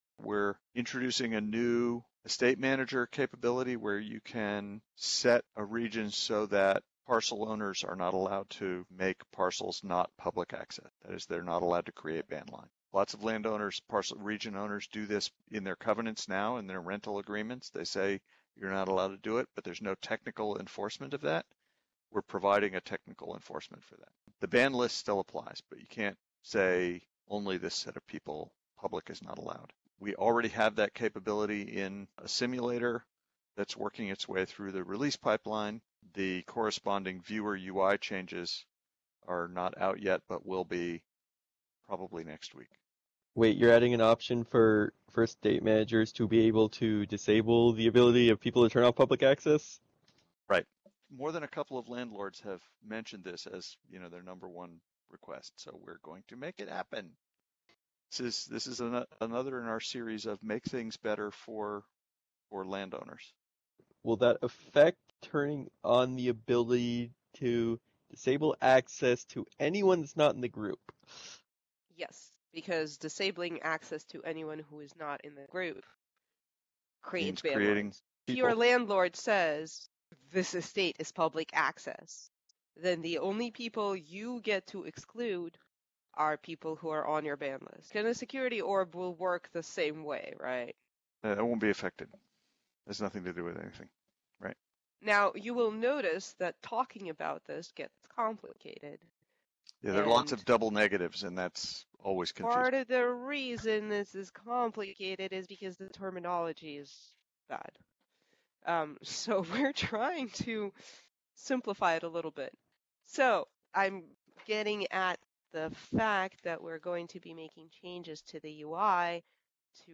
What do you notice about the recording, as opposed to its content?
The changes and the upcoming viewer UI updates were also discussed at the TPV Developer meeting, from which the following audio excerpts were extracted and put together.